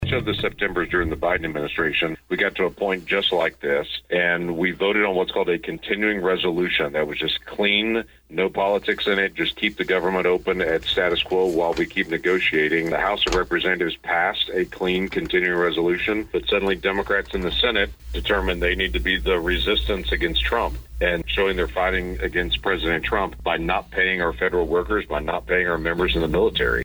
US Senator Lankford called into Bartlesville Radio for our monthly podcast to talk about the government shutdown.